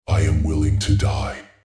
Subject description: A very electronic infantry unit voice!
This product is a self-made matching voice for my campaign infantry unit, the Cybertron Brute "ogre",which is completely self dubbed and not taken from any RTS of the same series.